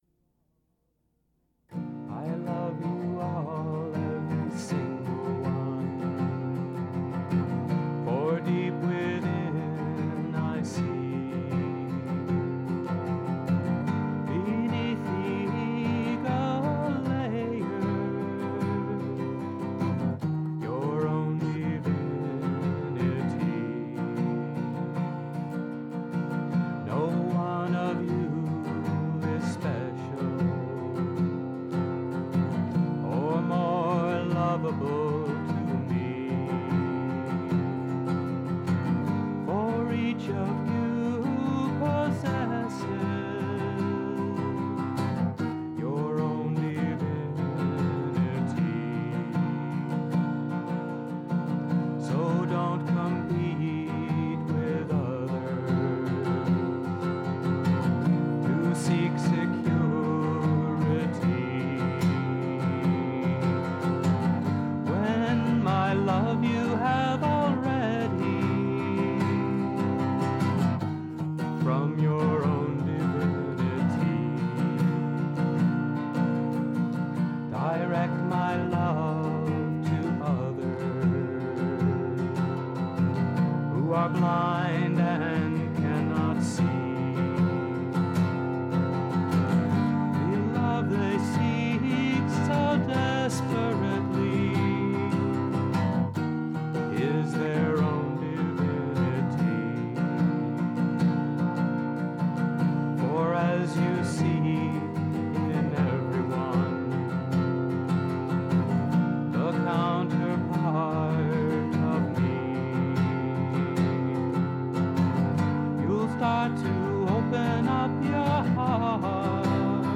1. Devotional Songs
Major (Shankarabharanam / Bilawal)
8 Beat / Keherwa / Adi
1 Pancham / C
5 Pancham / G
Lowest Note: S / C
Highest Note: G2 / E (higher octave)
* Western notes are in the Key of C